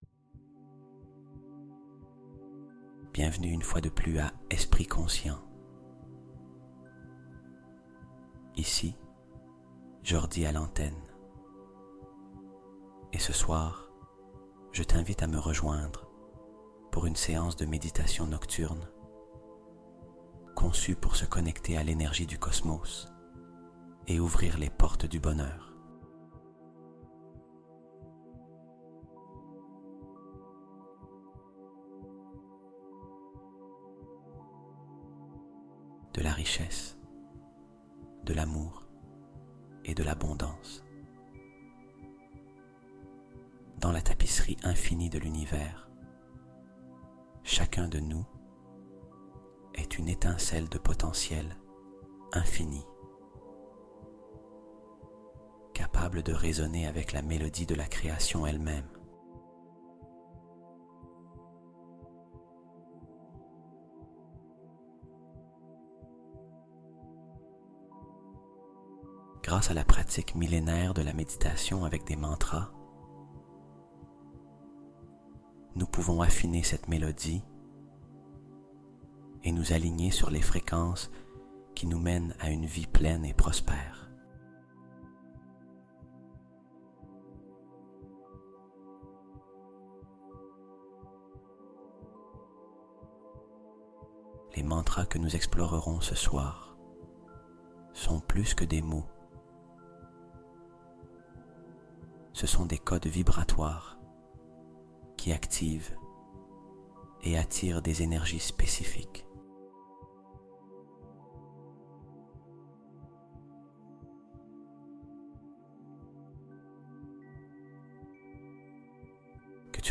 LES MANTRAS GARDÉS SECRETS DEPUIS 3000 ANS | 888 Hz Déclenche Miracles Et Bénédictions Instantanés